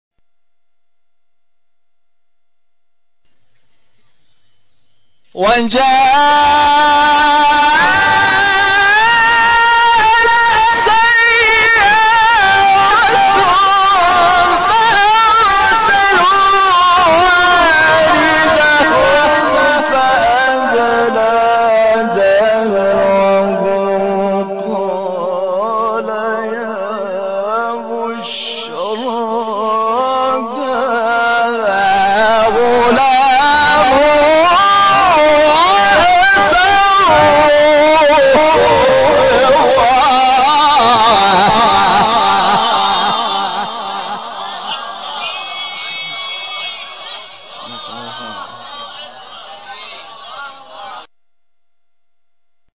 تلاوت سوره مریم استاد شاکرنژاد
تلاوت سی شب کمیل